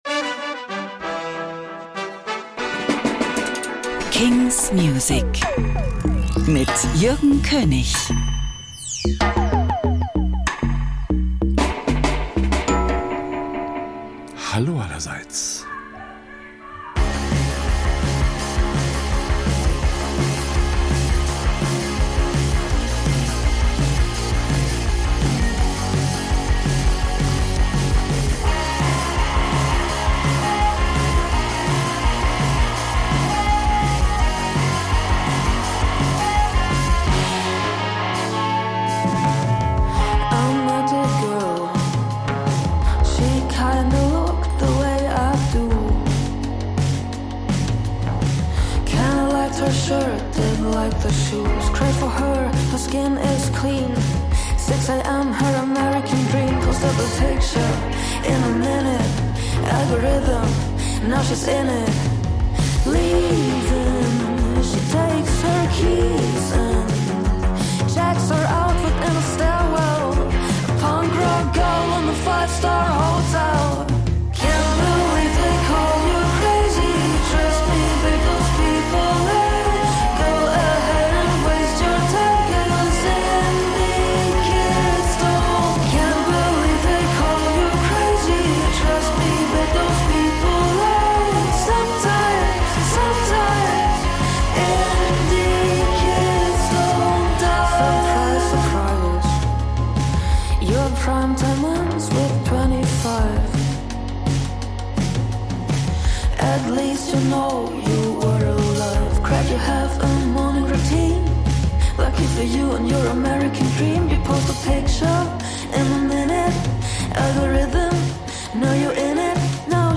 new indie & alternative releases.